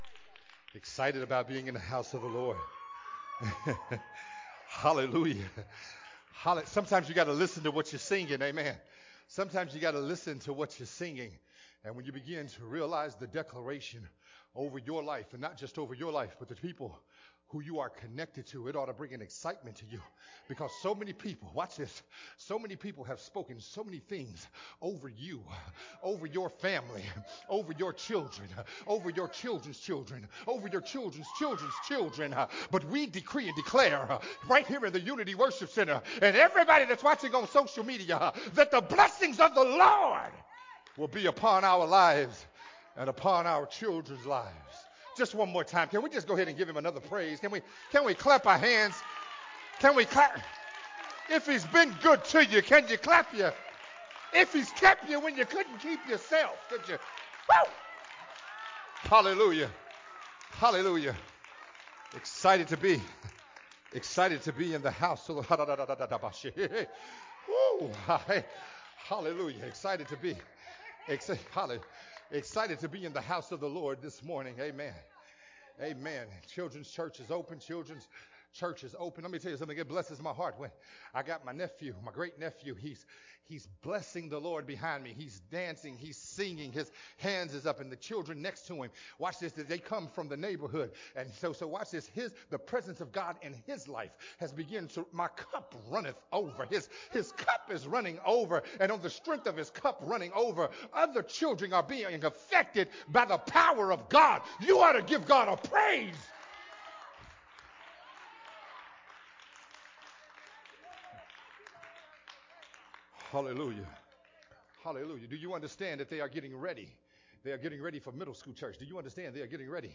Jesus knew how messed up we we would be but he died for us anyway. Recorded at Unity Worship Center on August 29th, 2021.